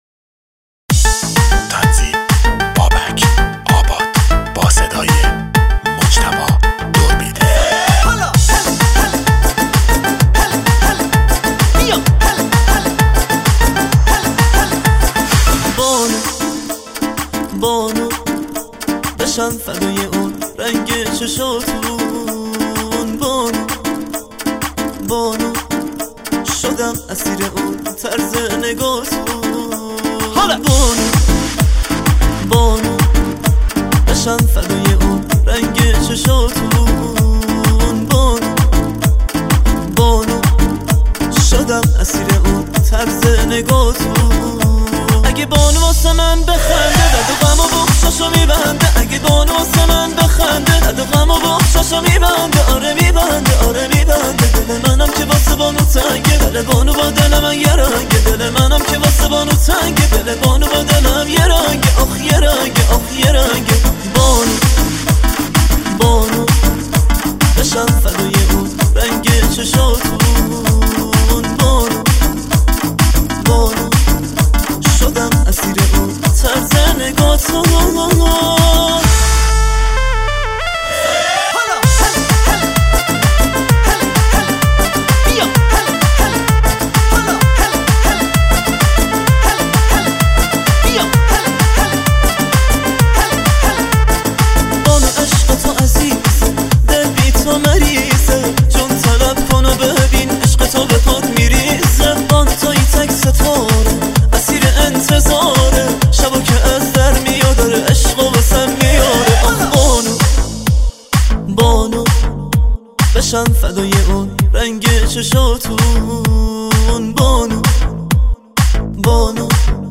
آهنگ مخصوص مراسم عروسی